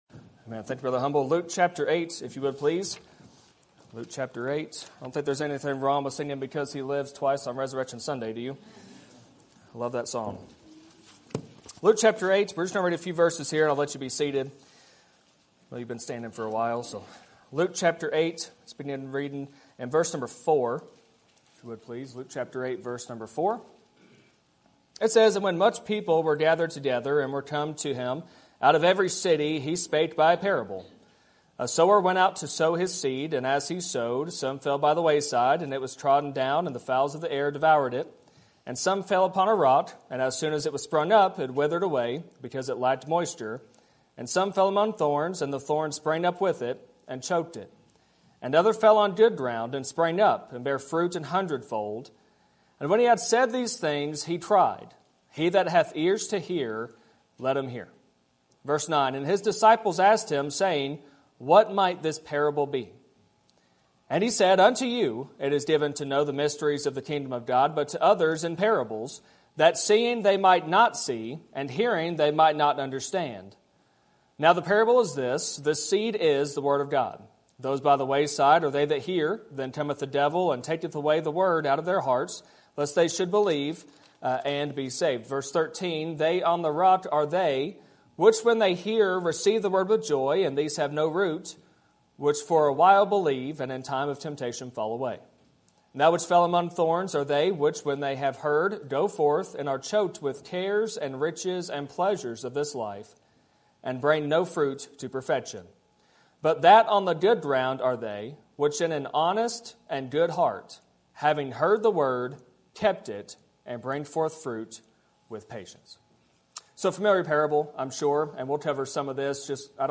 Sermon Topic: General Sermon Type: Service Sermon Audio: Sermon download: Download (18.64 MB) Sermon Tags: Luke Jesus Word People